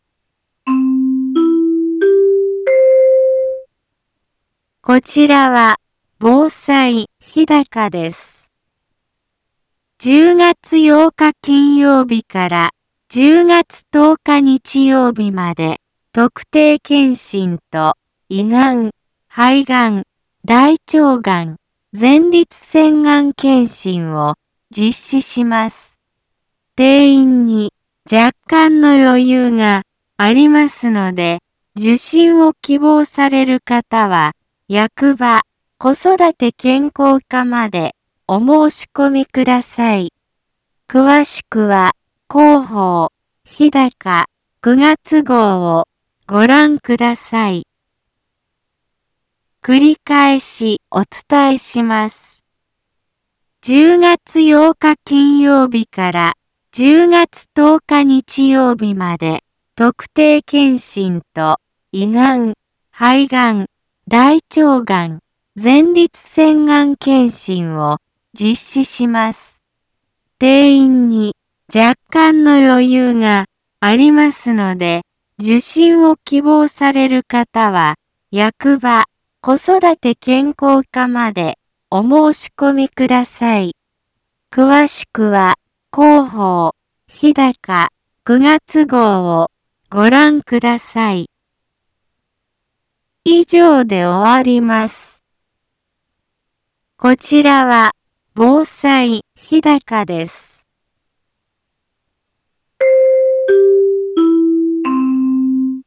一般放送情報
Back Home 一般放送情報 音声放送 再生 一般放送情報 登録日時：2021-09-01 10:03:44 タイトル：特定検診・がん検診のお知らせ インフォメーション：こちらは防災日高です。 10月８日金曜日から10月10日日曜日まで、特定検診と胃がん・肺がん・大腸がん・前立せんがん検診を実施します。